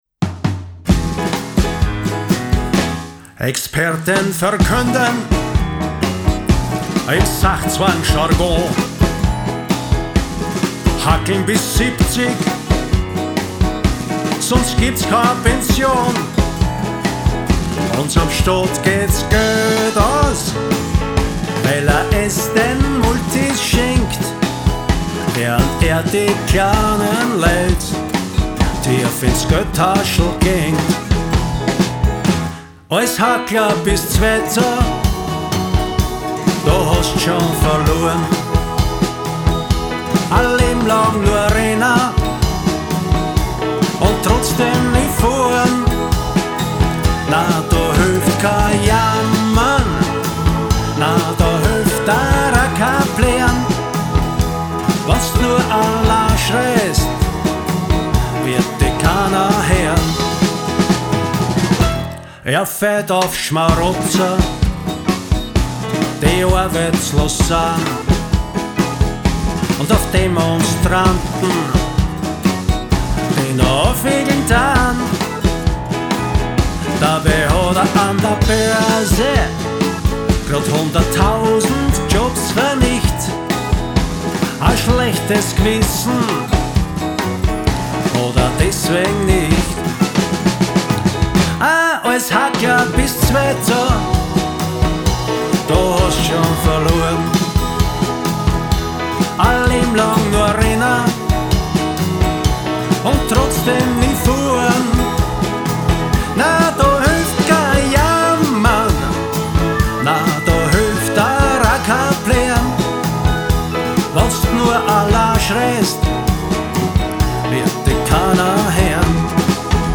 Aufnahme & Mix & Studioarrangements
Digeridoo
Konzertgitarre